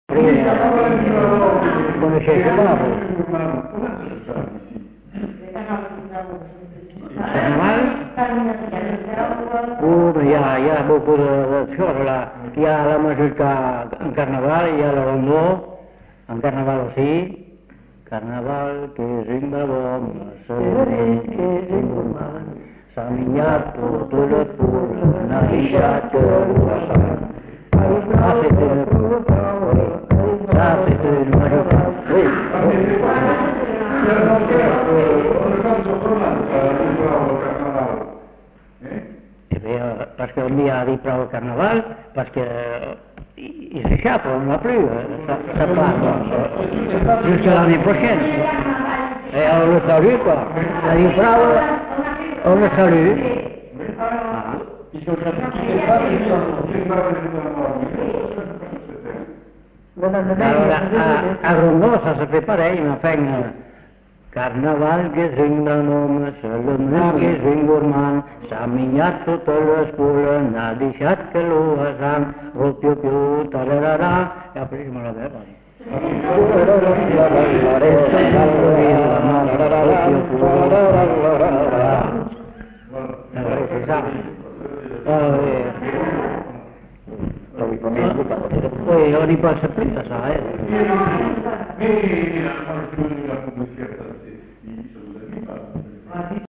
Aire culturelle : Bazadais
Lieu : Cazalis
Genre : chant
Effectif : 2
Production du son : chanté
Danse : mazurka
Classification : chanson de carnaval